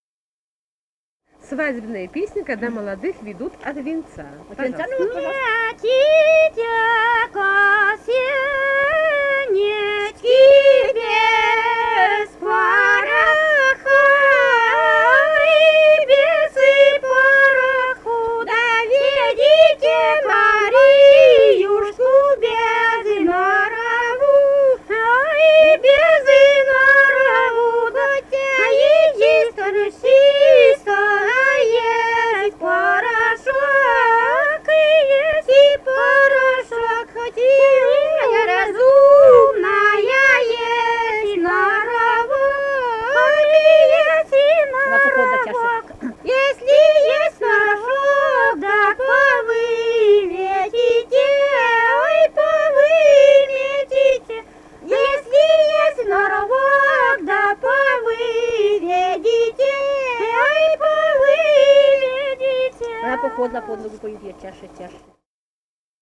Этномузыкологические исследования и полевые материалы
Костромская область, с. Дымница Островского района, 1964 г. И0789-24